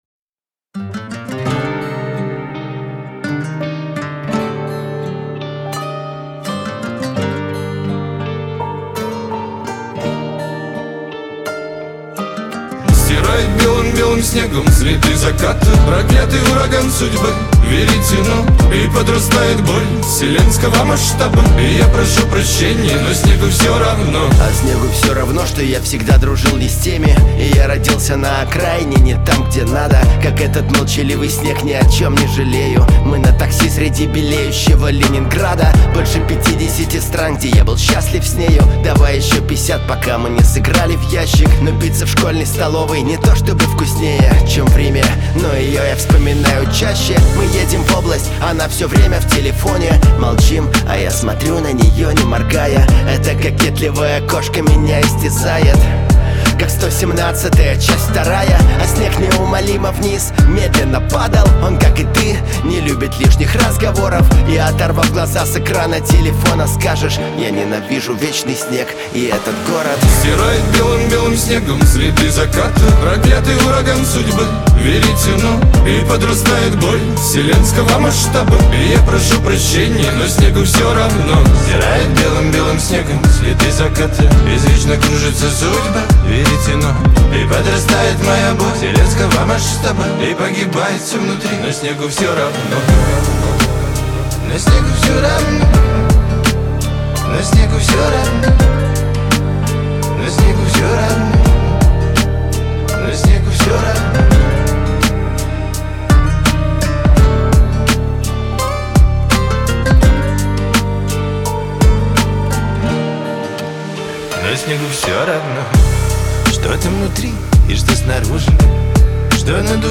диско , дуэт
Шансон